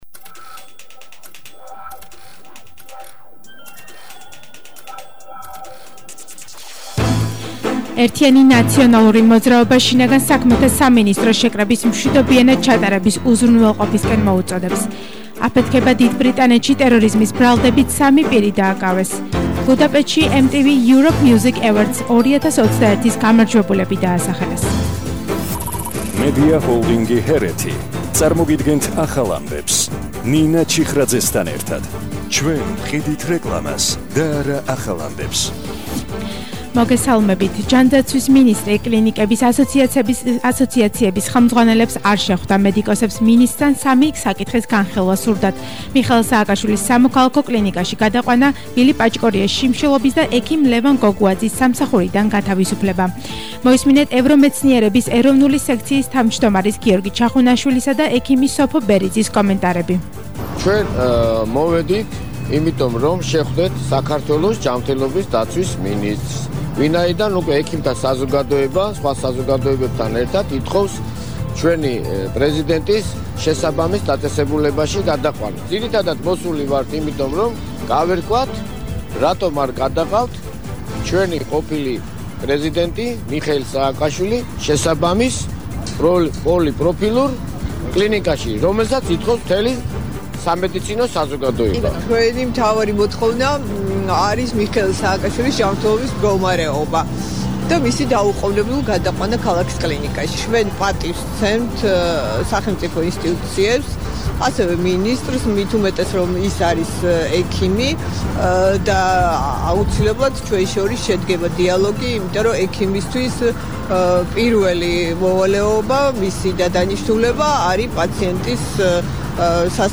ახალი ამბები 14:00 საათზე –15/11/21